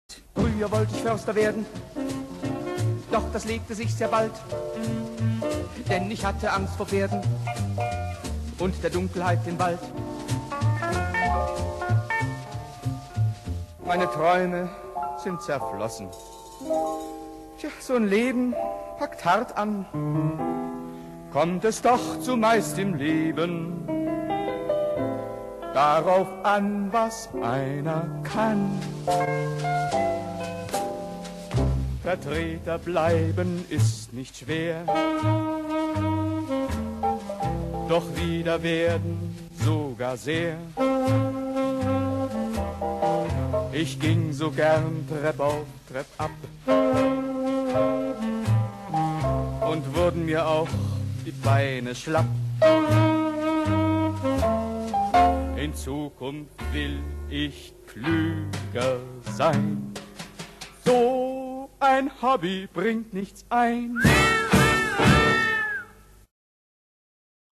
Rundfunkmusical (Hörspiel)